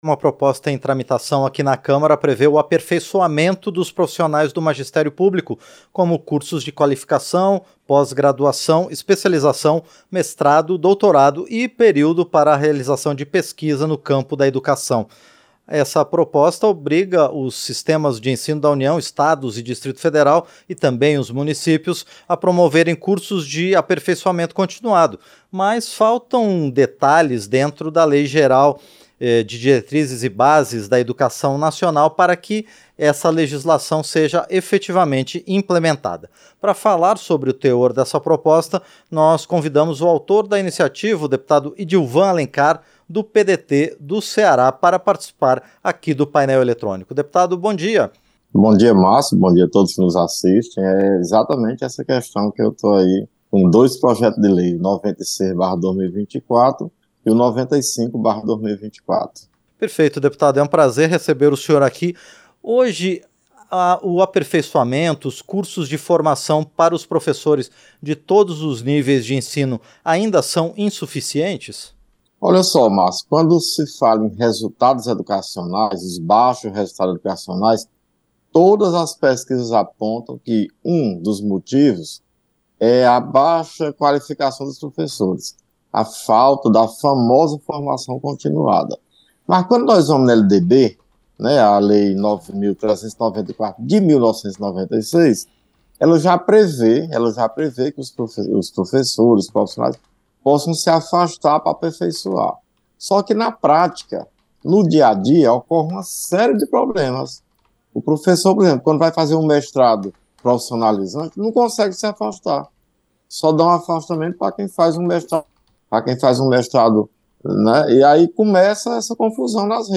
Entrevista - Dep. Idilvan Alencar (PDT-CE)